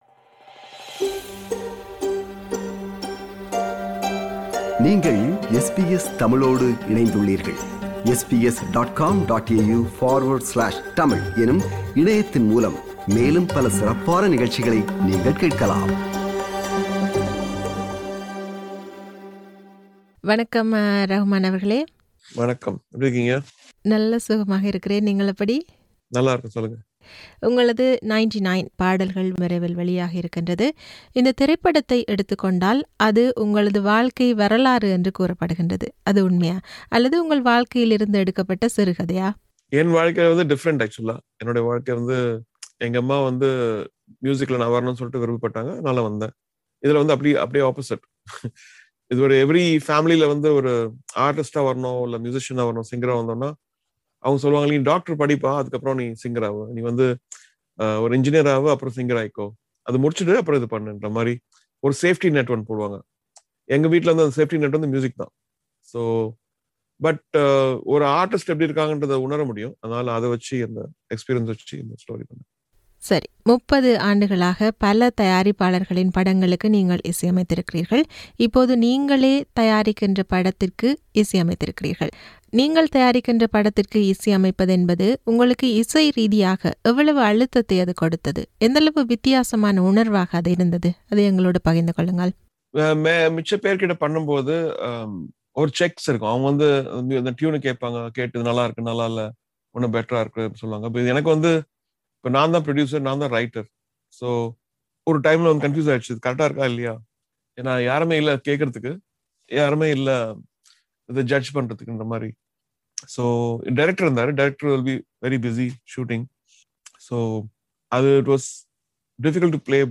இசையமைப்பாளர் ஏ ஆர் ரஹ்மானுடன் ஓர் உரையாடல் !